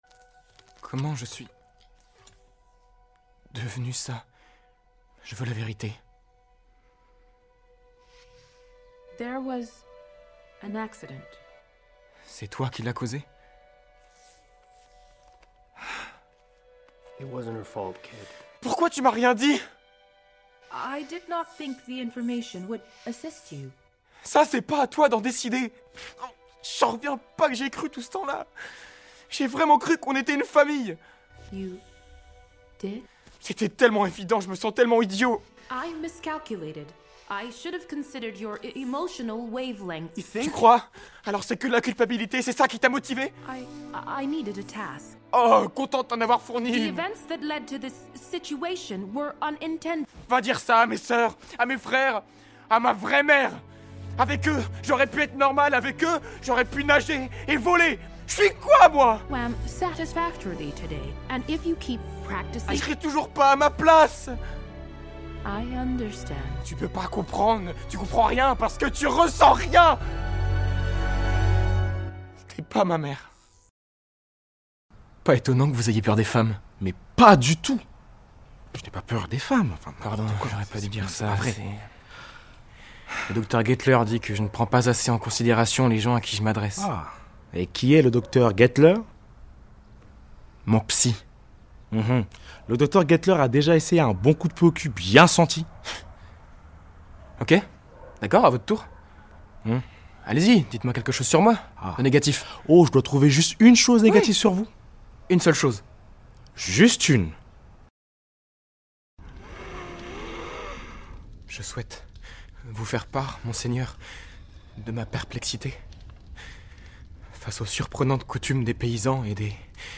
Démo doublage (Wild Robot, Winterbreak, Nosferatu)
15 - 35 ans